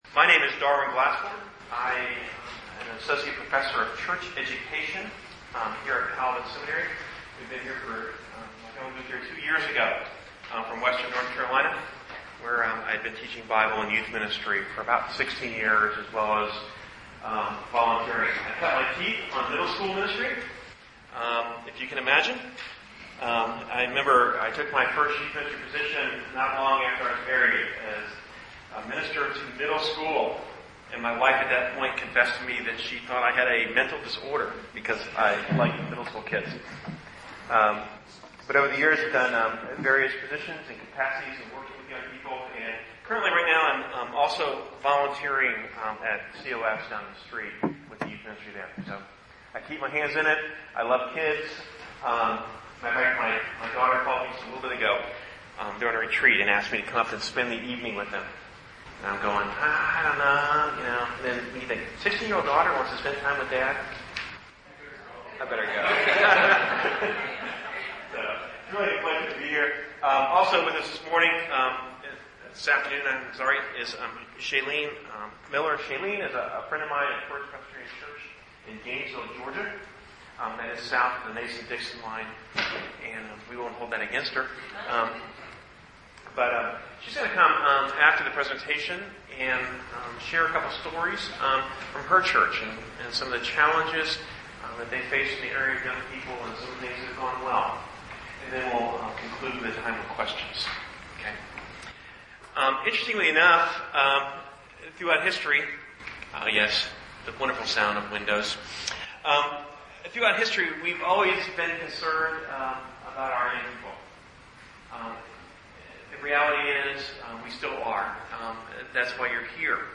Presented at the 2007 Calvin Symposium on Worship.